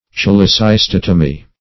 Meaning of cholecystotomy. cholecystotomy synonyms, pronunciation, spelling and more from Free Dictionary.
Search Result for " cholecystotomy" : The Collaborative International Dictionary of English v.0.48: Cholecystotomy \Chol`e*cys*tot"o*my\, n. [Cholecystis + Gr. te`mnein to cut.]